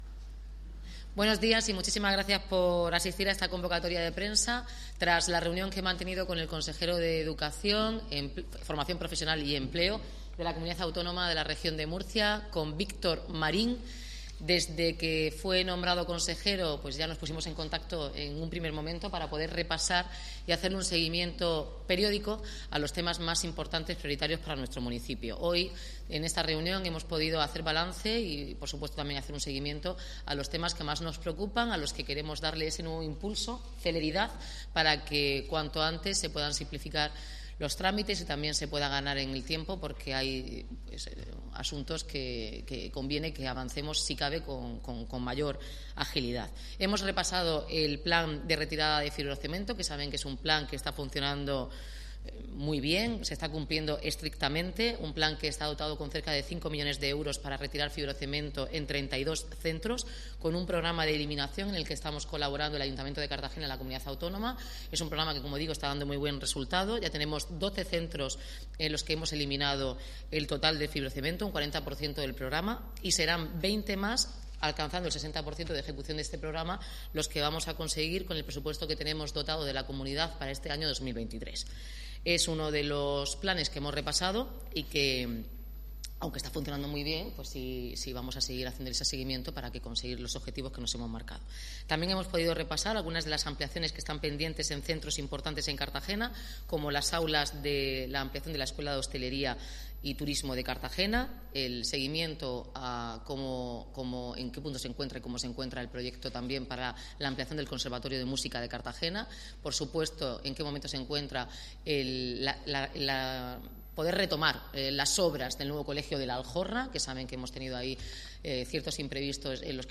Enlace a Declaraciones de Noelia Arroyo y Víctor Marín